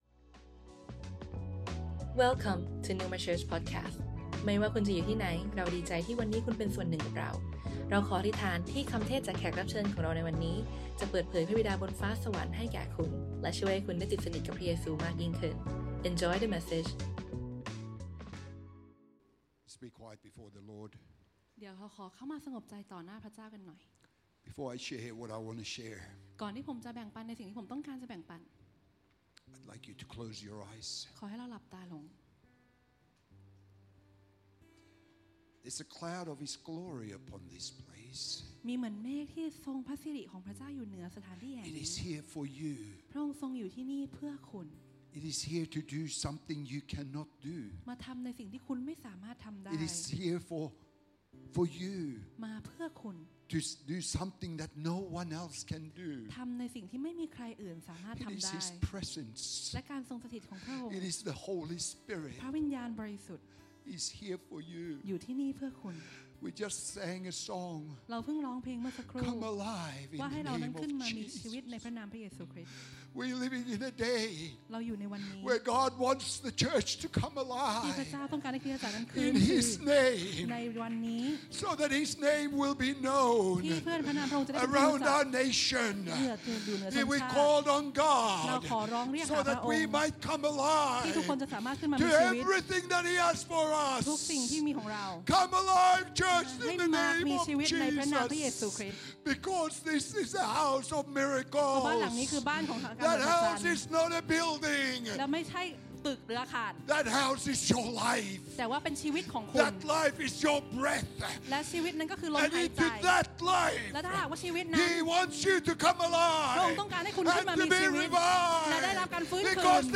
Originally recorded on Sunday 7th April 2024, at Neuma Bangkok.